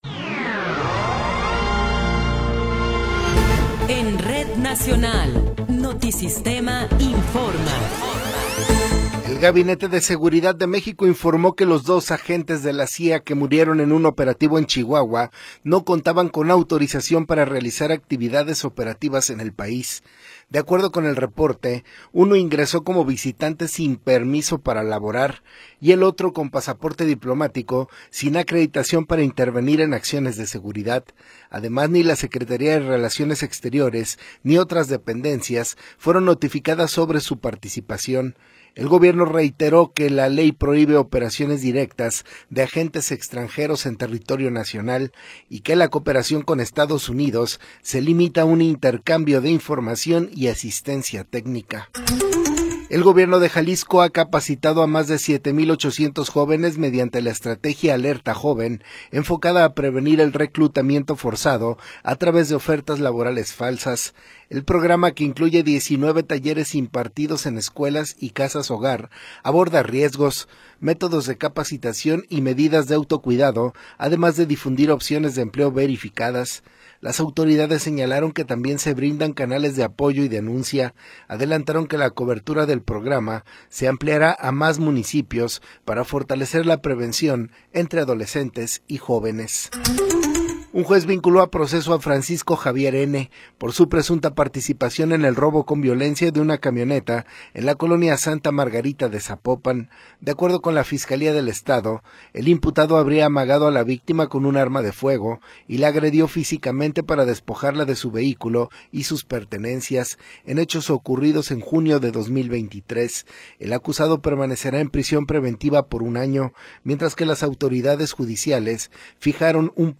Noticiero 11 hrs. – 25 de Abril de 2026
Resumen informativo Notisistema, la mejor y más completa información cada hora en la hora.